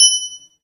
ding_0.ogg